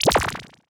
Sci-Fi Click Back.wav